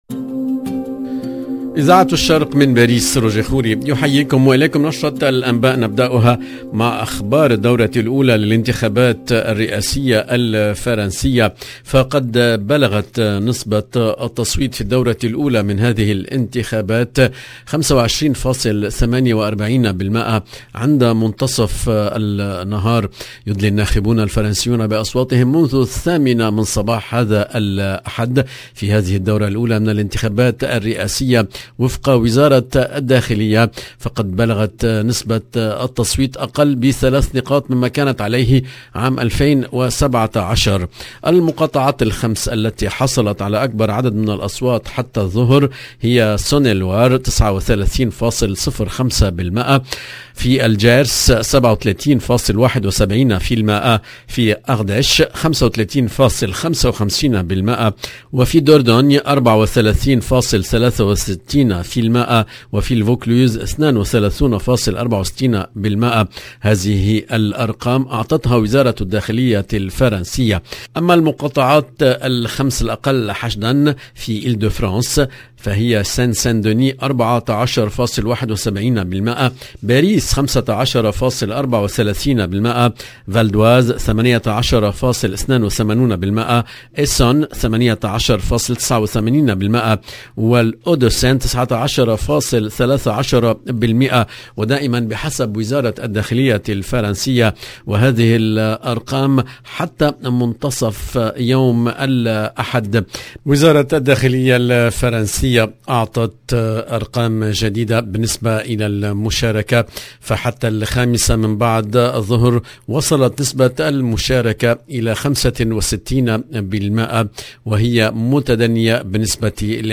EDITION DU JOURNAL DU SOIR EN LANGUE ARABE DU 10/4/2022